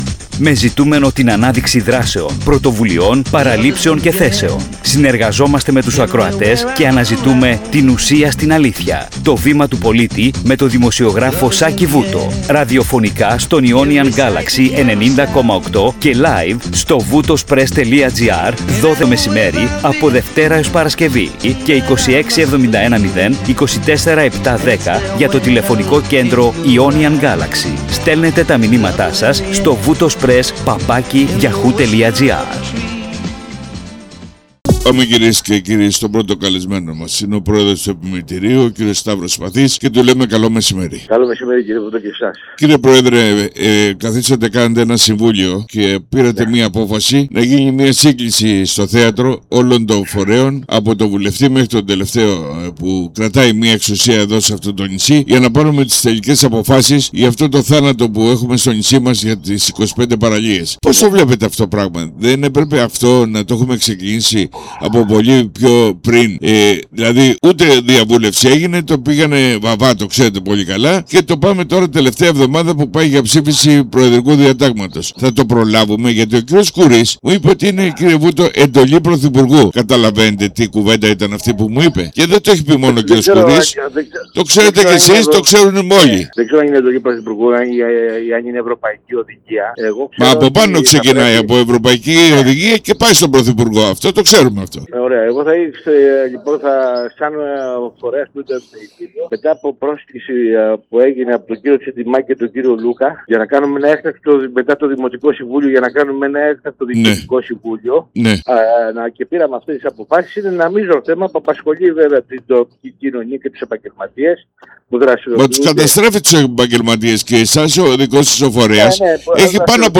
🎙 Συνέντευξη στον Ionian Galaxy 90.8